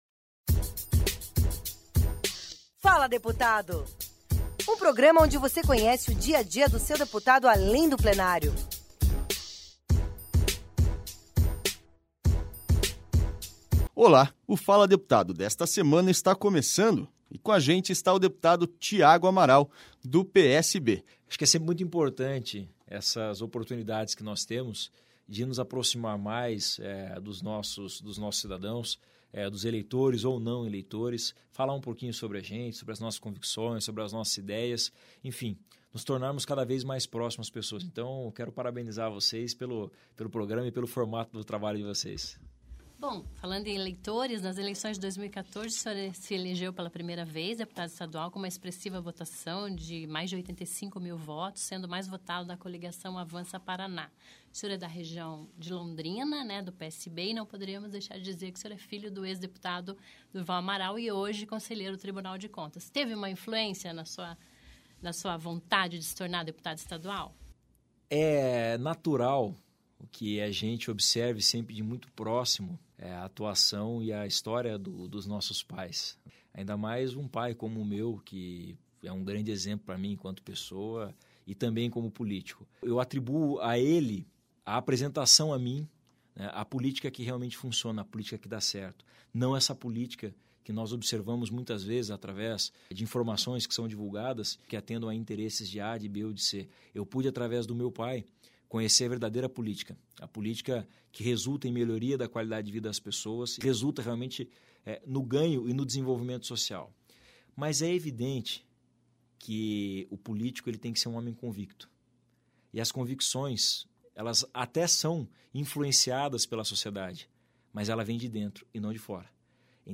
Tiago Amaral é o entrevistado do programa da Rádio Assembleia desta semana.